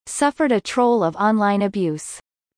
以下、設問１）〜４）の不正解答案文を音読したネイティブ音声を出題しました。
不正解答案の読み上げ音声
torrent（連発）」と「troll（荒らし）」の発音・綴りを区別しよう！